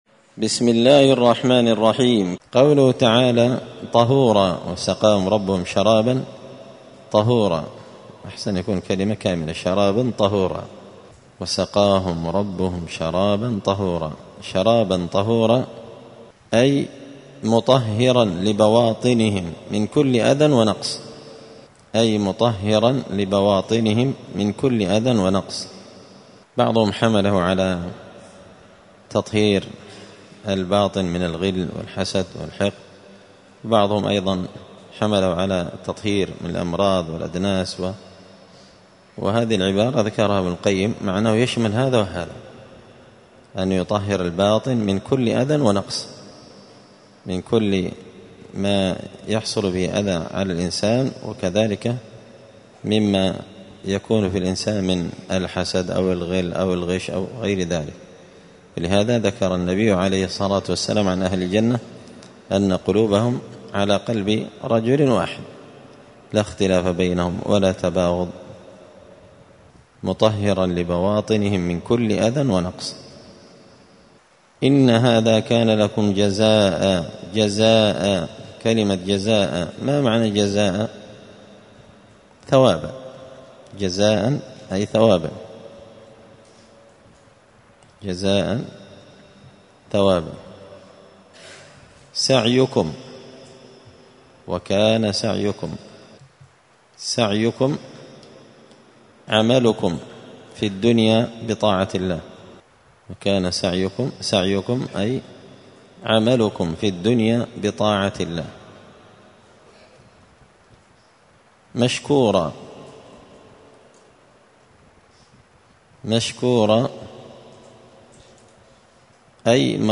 *(جزء تبارك سورة الإنسان الدرس 113)*